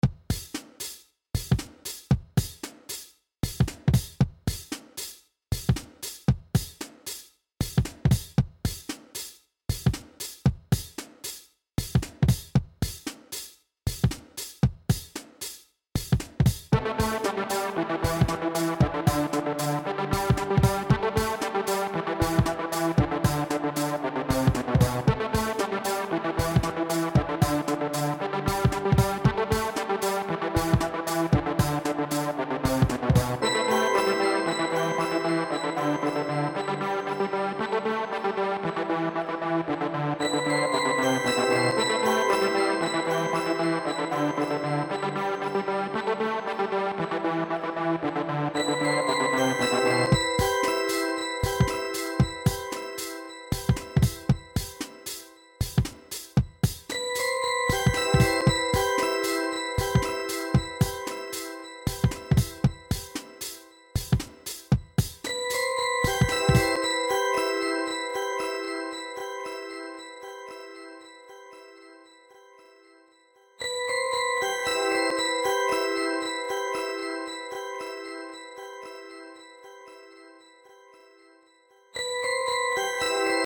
• Жанр: Легкая
У данной композиции отсутствует текст.